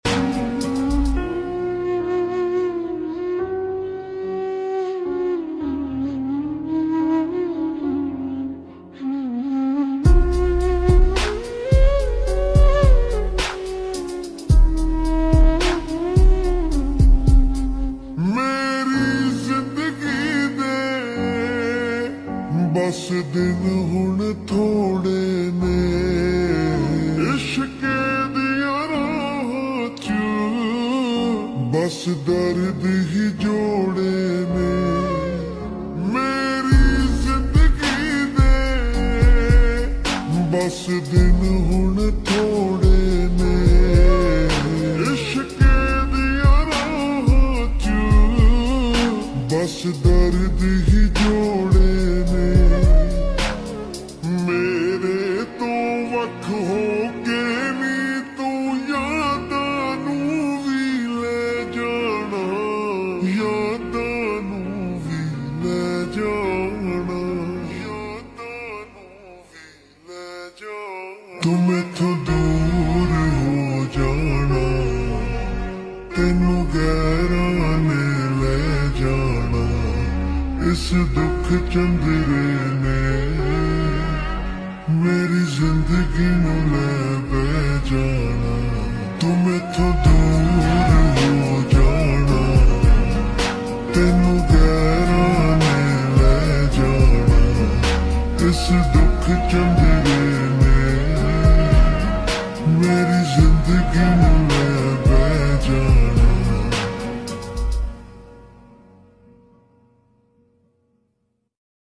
𝕤𝕝𝕠𝕨𝕖𝕕 ℝ𝕖𝕧𝕖𝕣𝕓 𝕞𝕦𝕤𝕚𝕔